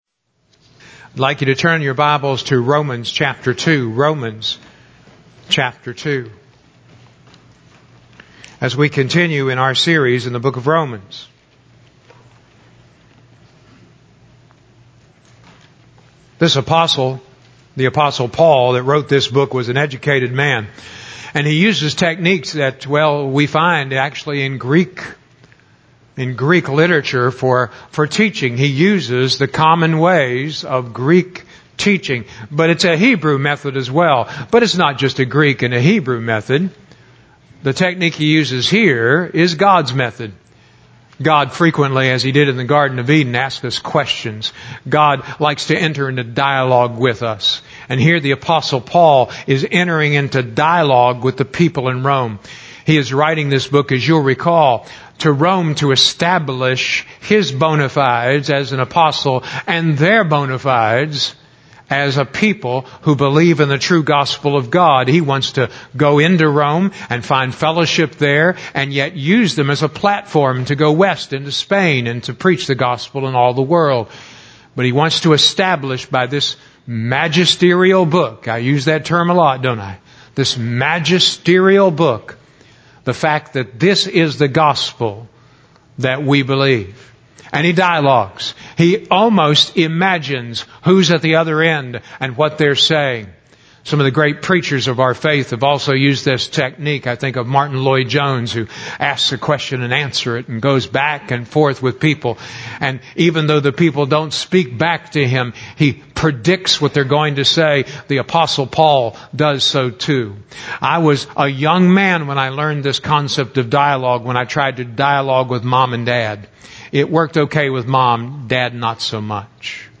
July 29, 2018 Sermon Notes: I. Remember, God _______________ it ____________.